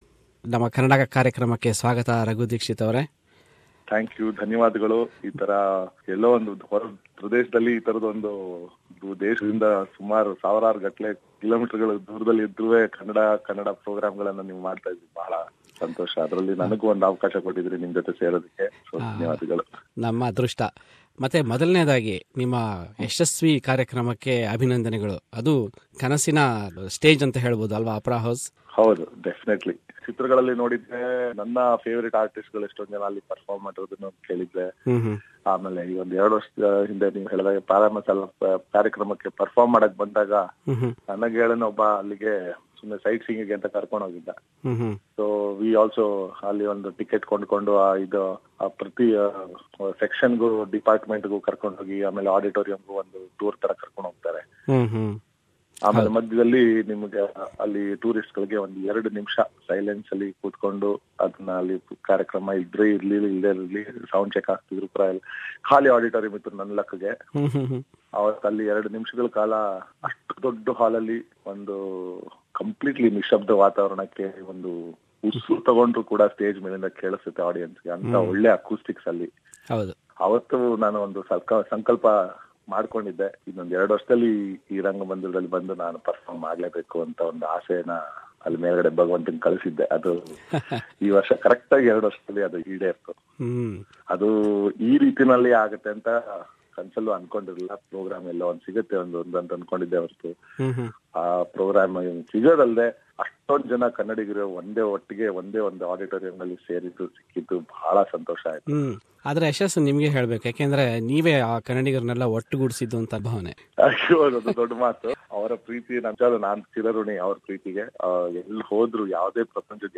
A reputed singer Raghu Dixits interview